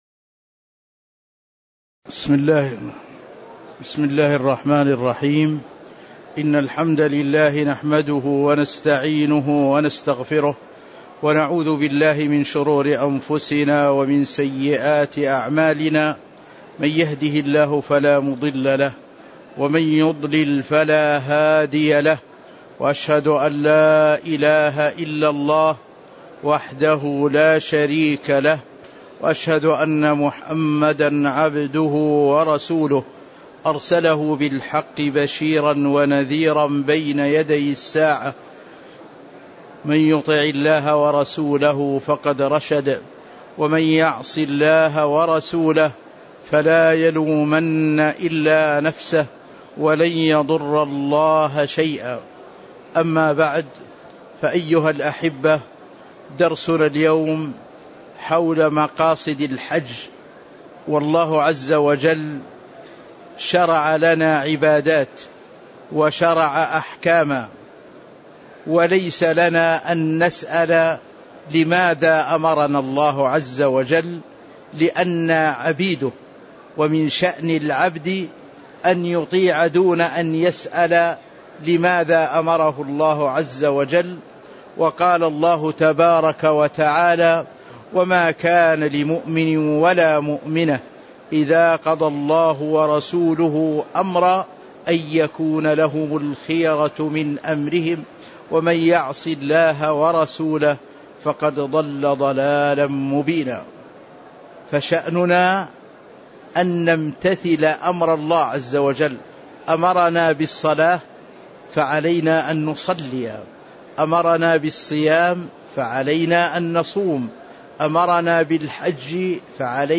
تاريخ النشر ٢٨ ذو القعدة ١٤٤٣ هـ المكان: المسجد النبوي الشيخ